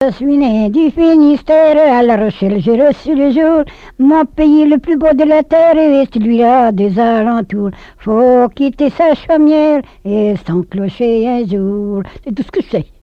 Genre strophique
collecte en Vendée
répertoire de chansons, et d'airs à danser
Pièce musicale inédite